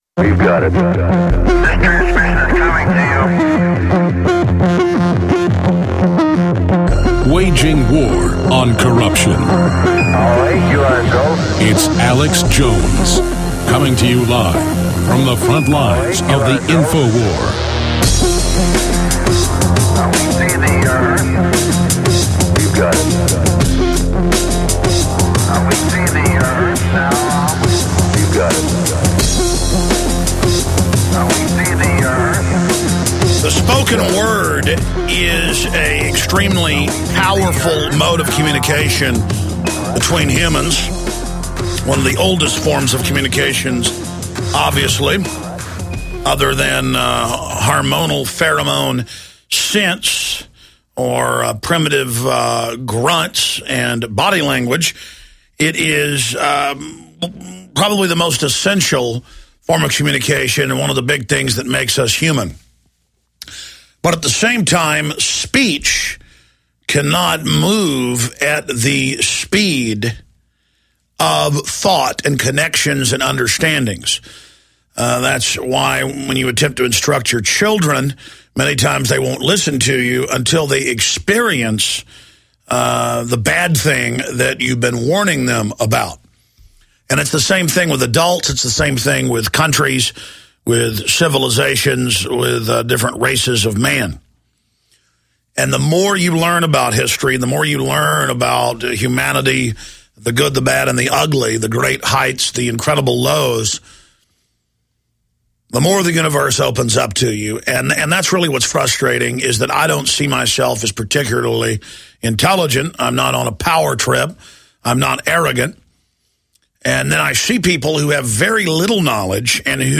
Alex Jones Show Commercial Free Podcast